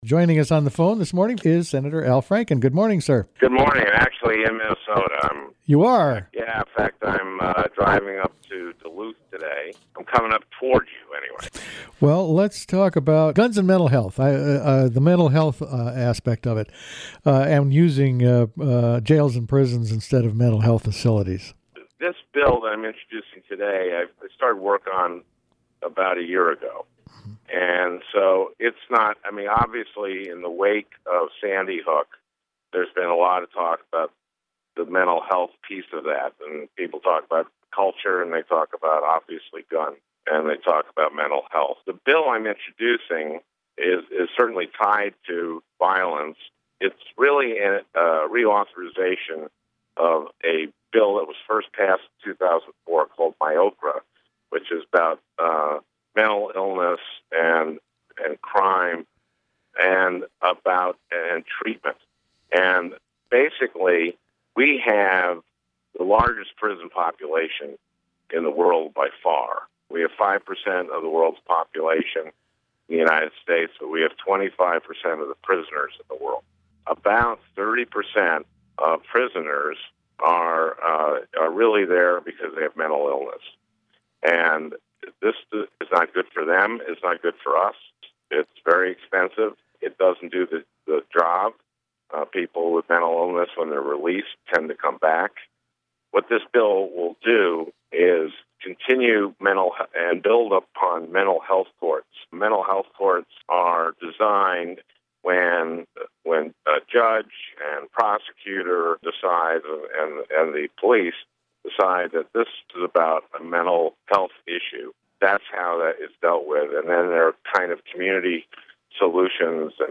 spoke with Minnesota’s US Senator Al Franken.